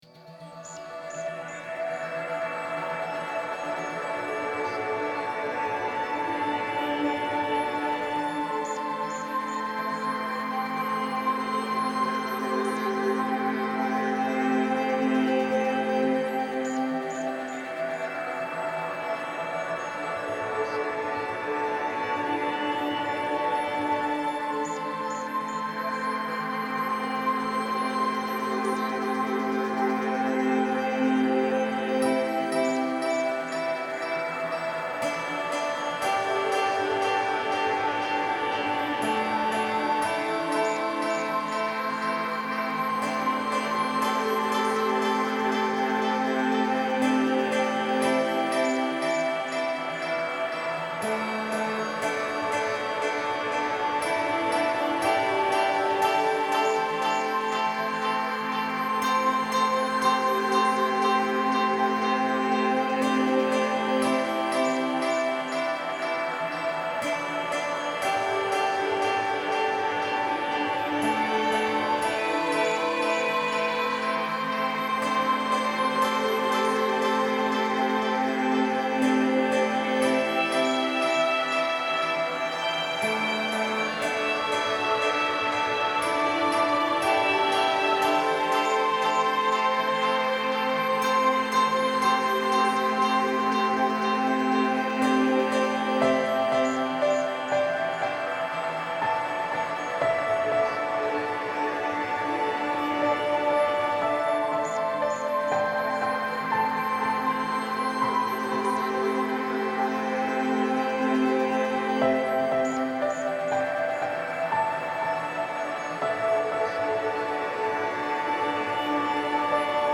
This is a loopable piece that would be great of a main menu, or a town or village. Pretty simple with a great atmosphere.